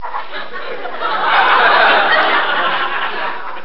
Tags: grindcore comedy horror gore insult